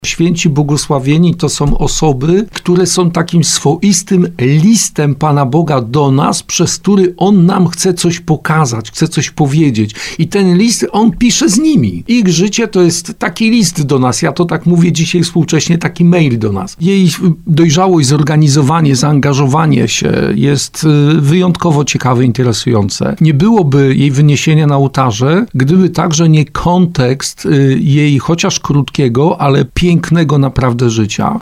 W Sanktuarium bł. Karoliny Kózkówny w Zabawie odbyły się ogólnopolskie rekolekcje dla byłych asystentów Katolickiego Stowarzyszenia Młodzieży.